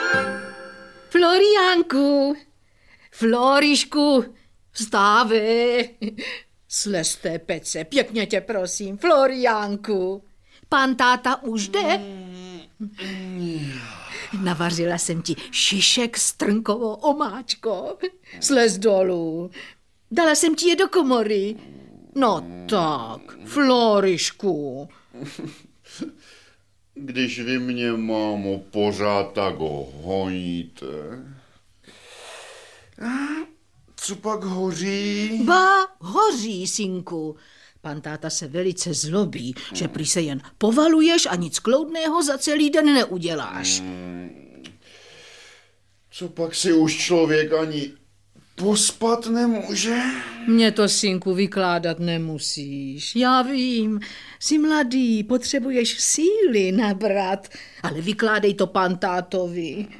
Audiobook
Audiobooks » Children & Youth, For Children, Poetry